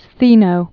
(sthēnō)